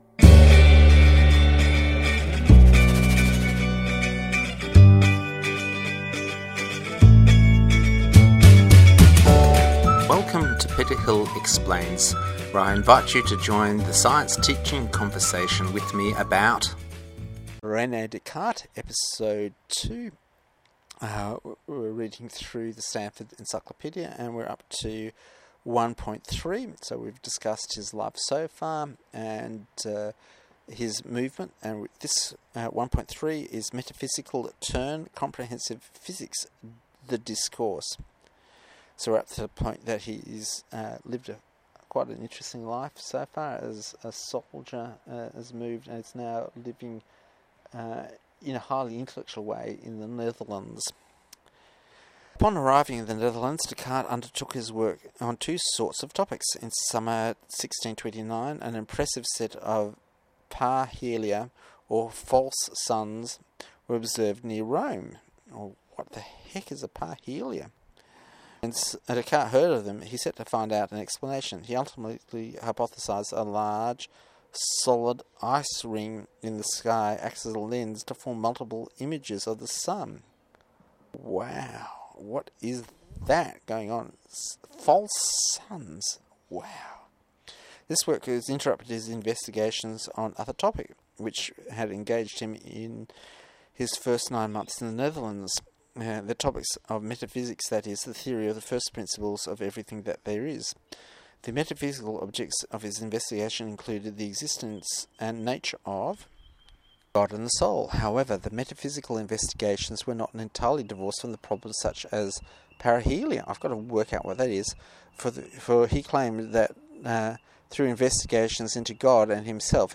A reading from the Standford Encyclopedea of Phyilosophy, First Project - looking for a theory of everything that does everything ...